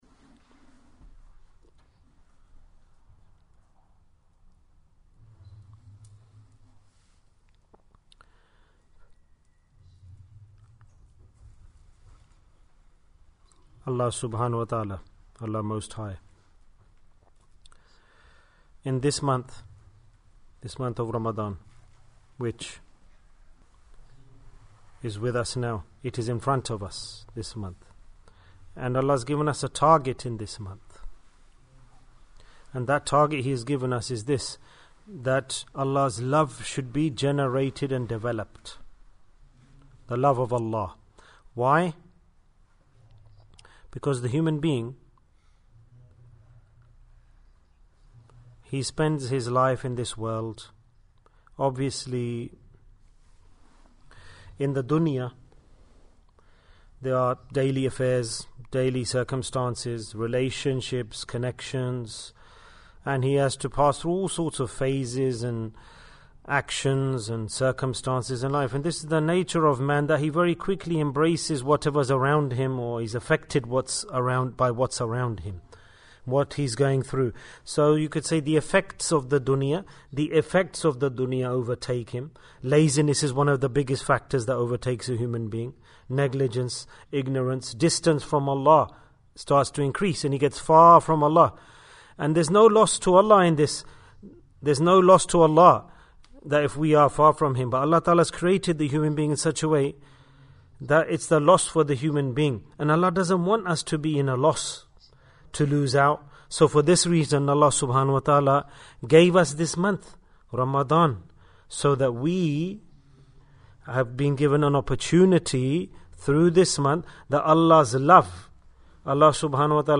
Jewels of Ramadhan 2025 - Episode 5 Bayan, 25 minutes4th March, 2025